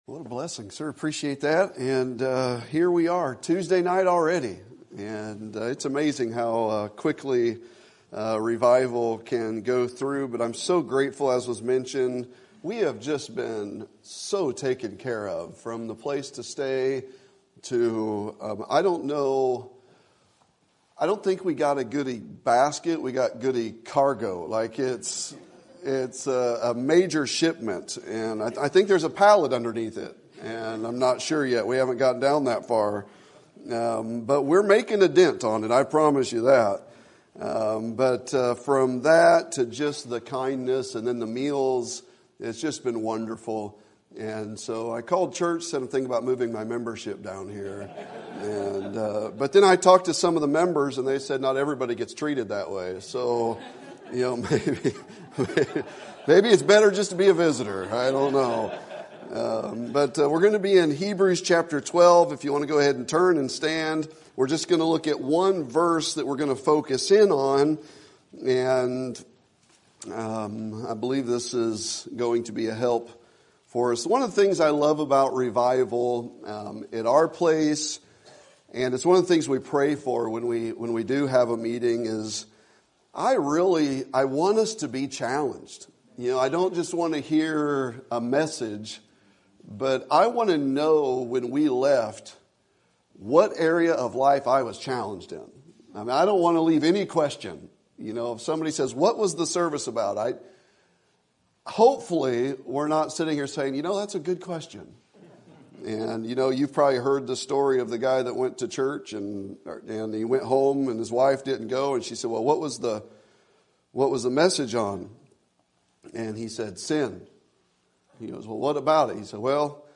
Sermon Topic: Winter Revival Sermon Type: Special Sermon Audio: Sermon download: Download (22.21 MB) Sermon Tags: Hebrews Relationships Hurt Bitterness